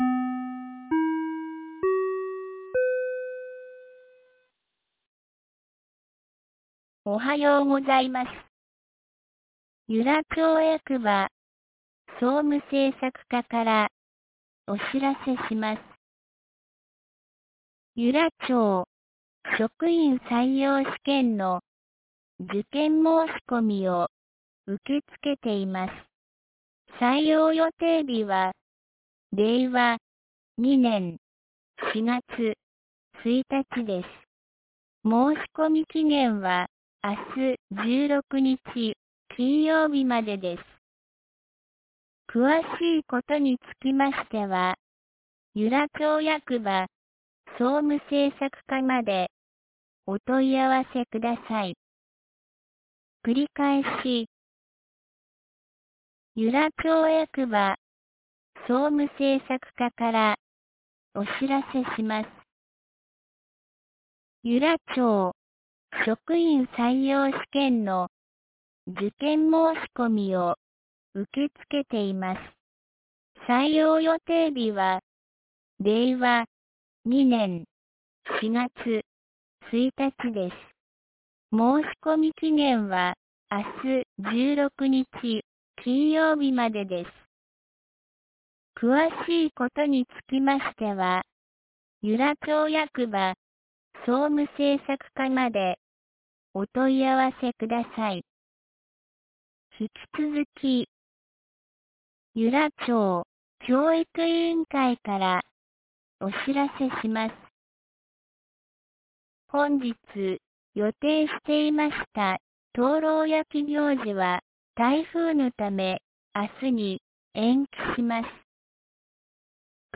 2019年08月15日 07時52分に、由良町から全地区へ放送がありました。
放送音声